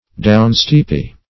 Downsteepy \Down"steep`y\